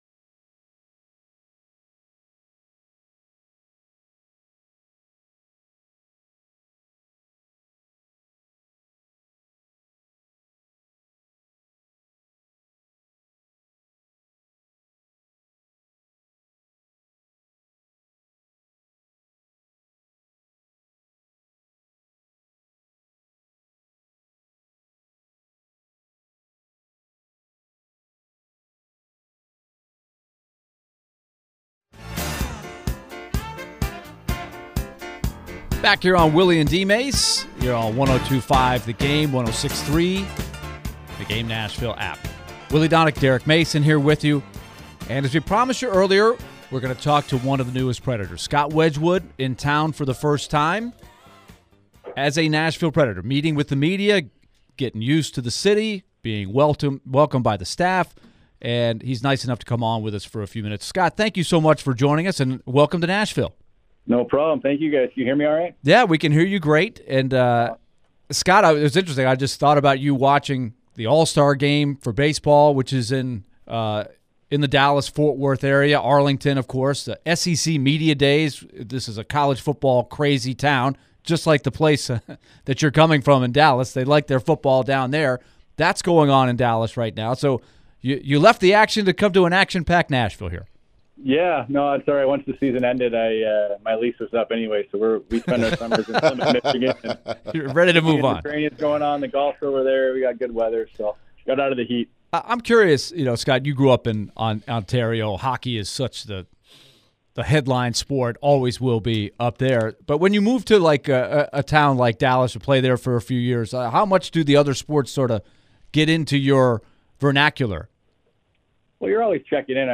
New Preds goalie Scott Wedgewood joined to talk about his move to Nashville, some of the best moments of his career, and how he plans to be a teammate to his completion.